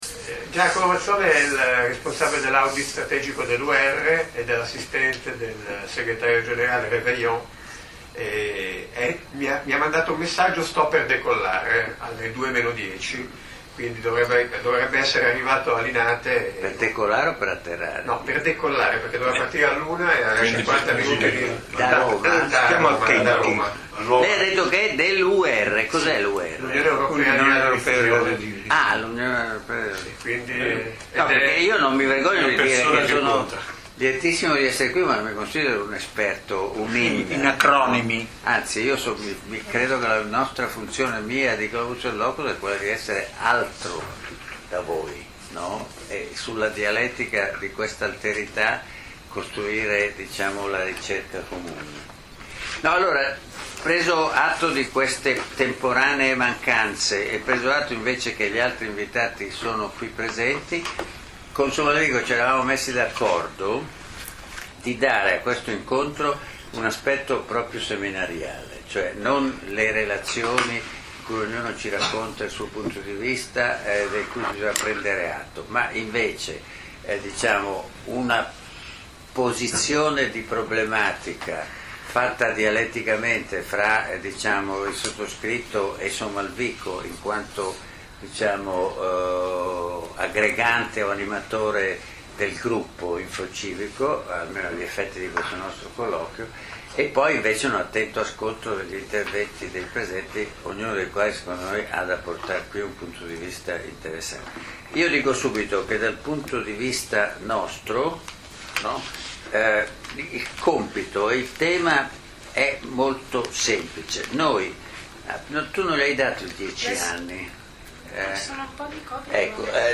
Seminario di Infocivica in collaborazione con Globus et Locus Milano - 15 gennaio 2009